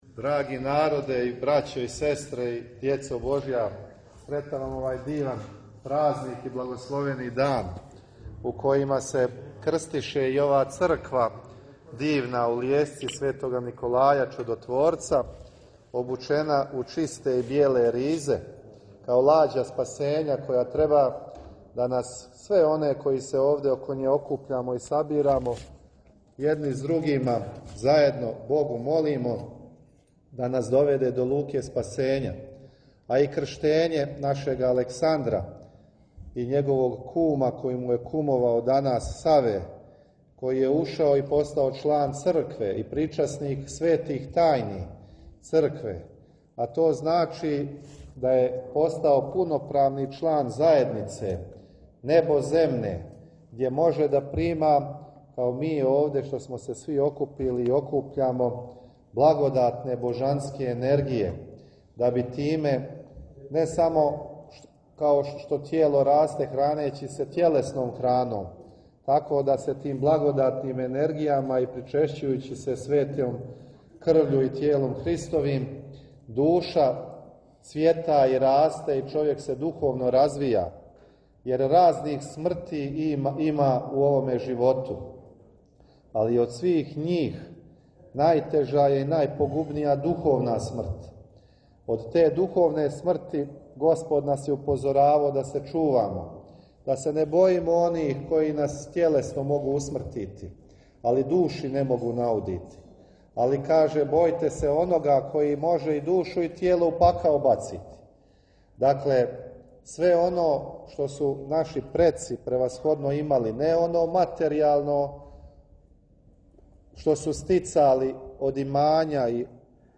Архијерејска Литургија и освећење цркве у Лијесци код Томашева